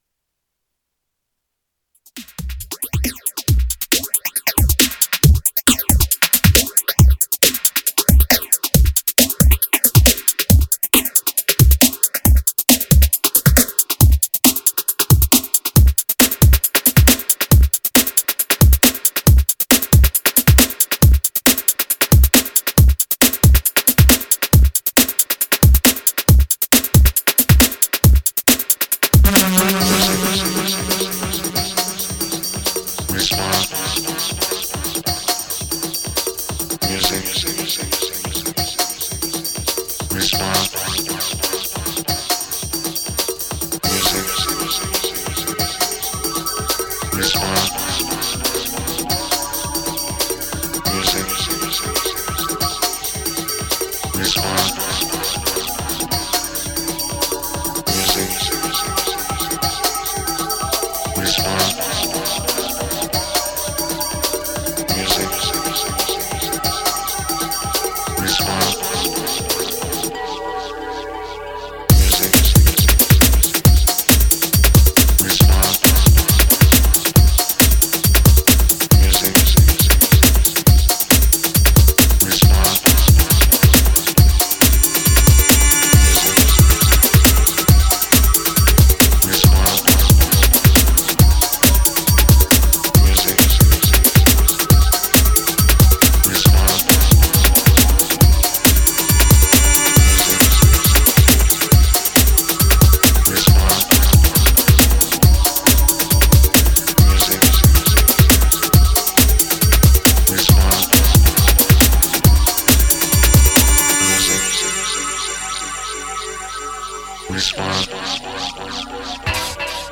ve kterém vám naservíruje směsici aktuálního breakbeatu !